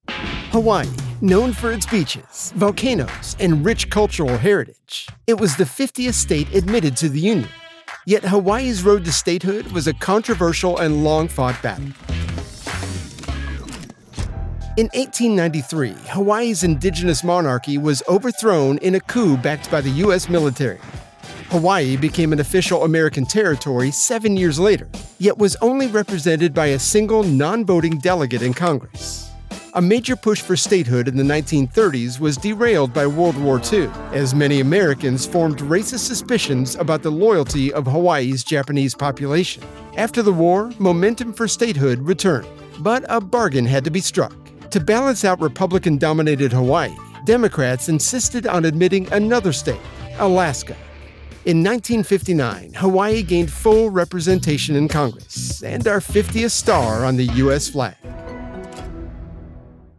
Announcer, conversational, non-announcer, guy next door, energy, high energy, Believable, Familiar, Natural, Upbeat, Real Person, Comforting, Friendly, Smooth, Sports, Persuasive, Classy, Authoritative, Big, Ba...
With ability to deliver from the confident or reserved guy next door appeal over to a deep, authoritative, godly resonance that will breathe life into any VO project.
0625Hawaii_History_Channel_promo.mp3